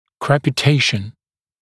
[ˌkrepɪ’teɪʃn][ˌкрэпи’тэйшн]крепитация, хруст